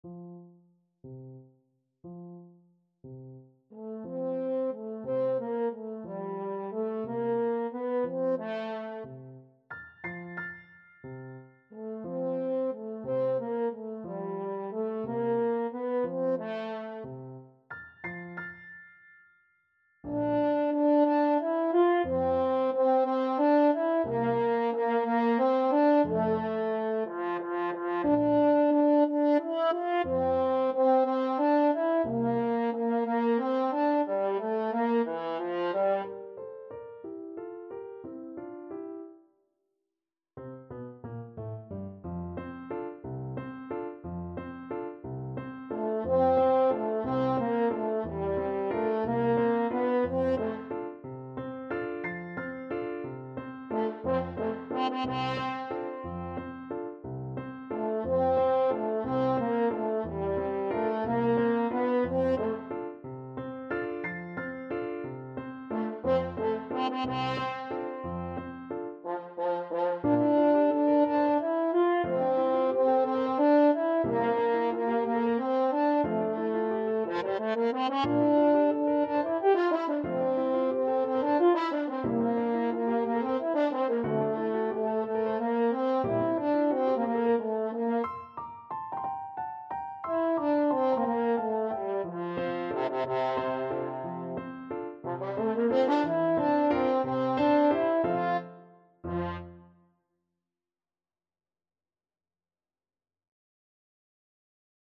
French Horn
6/8 (View more 6/8 Music)
C4-G5
F major (Sounding Pitch) C major (French Horn in F) (View more F major Music for French Horn )
Classical (View more Classical French Horn Music)